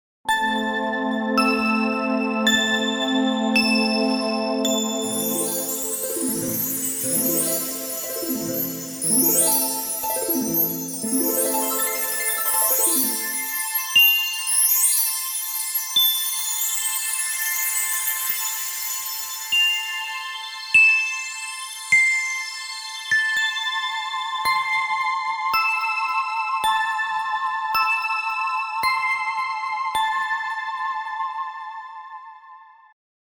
Звуки волшебной палочки
10 отличных эффектов для появления